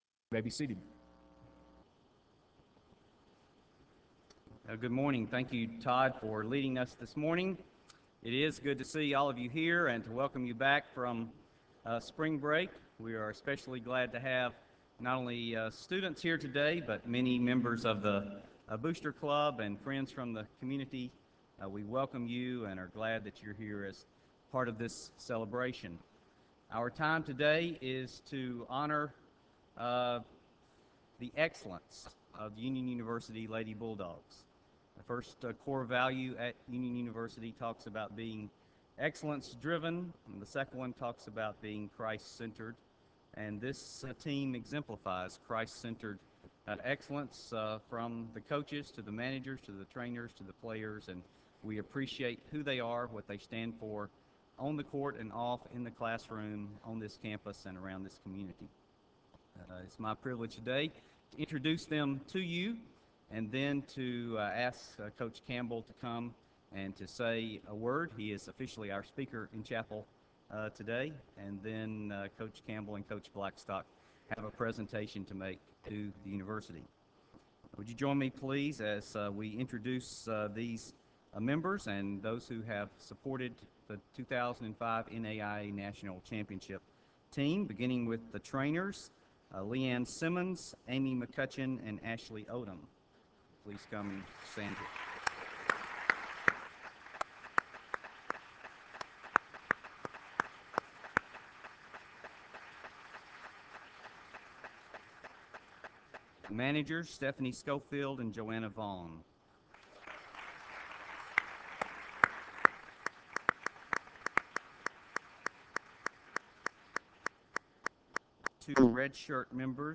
Chapel Service: Lady Bulldogs' National Championship Celebration